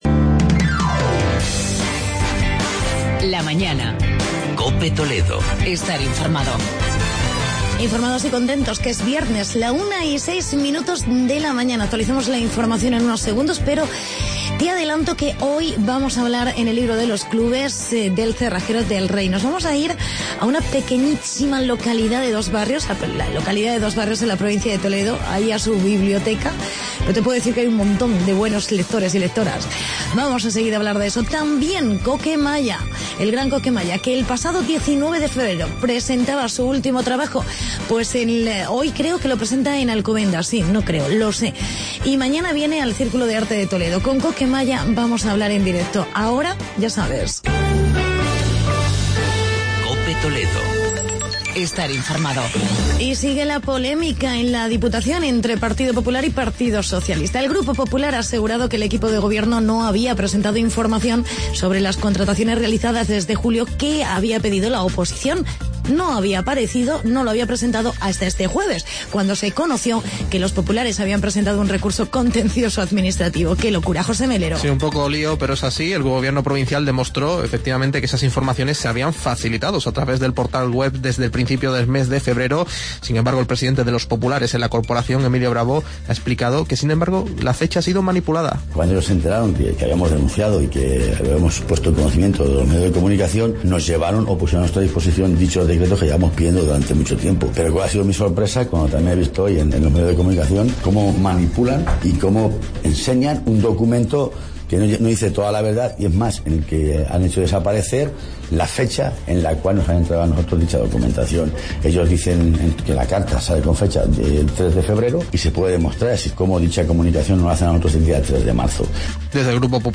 entrevistamos al cantante Coque Malla